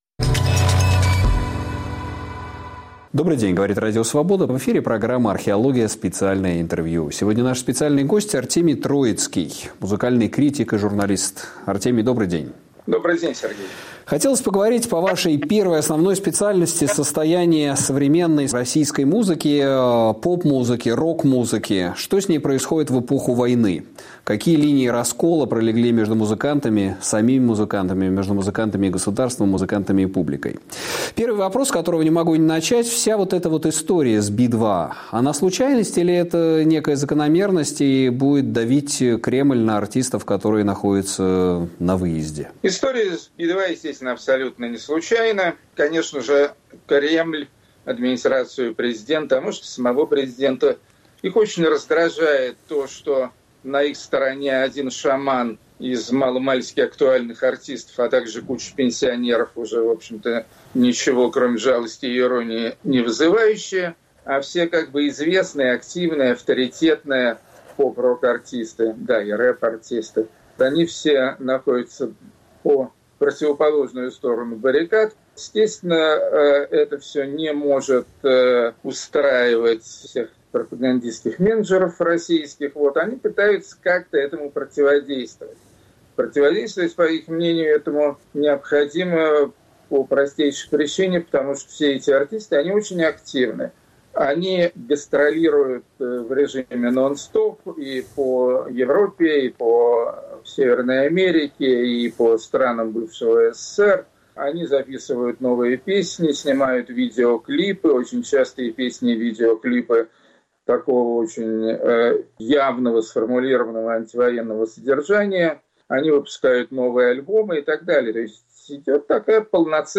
Гость Сергея Медведева- музыкальный критик Артемий Троицкий